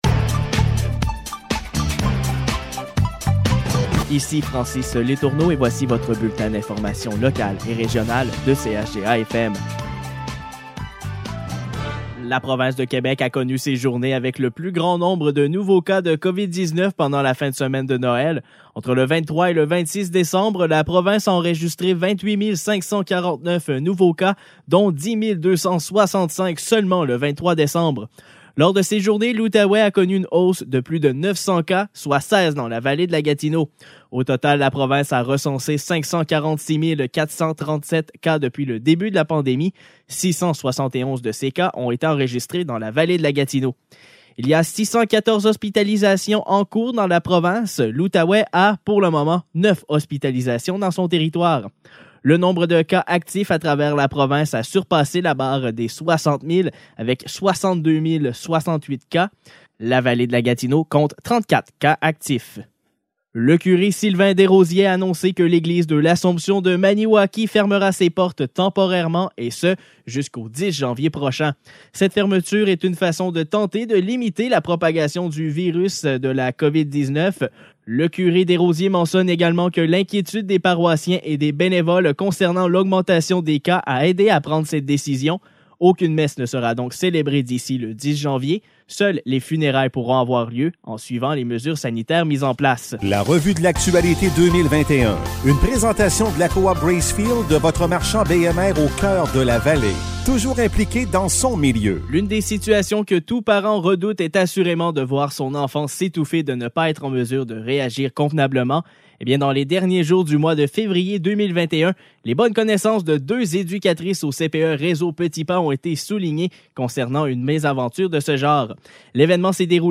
Nouvelles locales - 27 décembre 2021 - 16 h